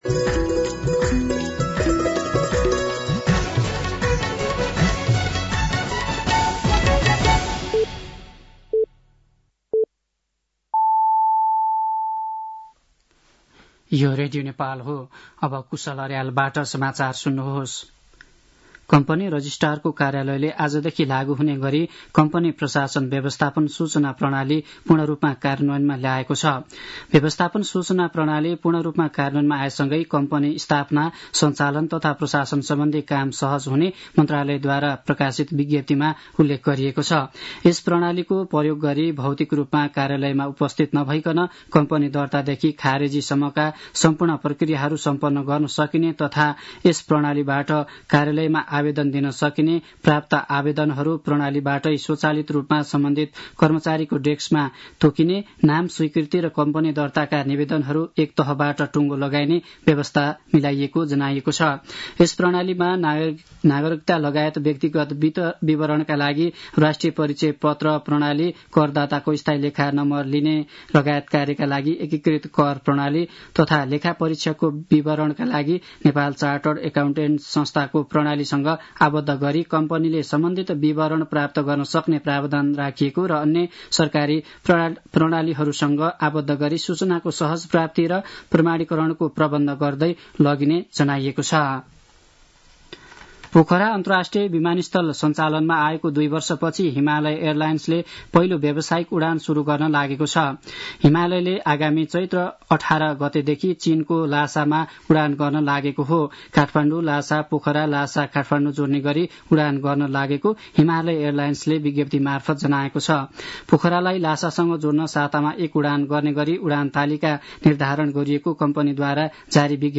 साँझ ५ बजेको नेपाली समाचार : २१ फागुन , २०८१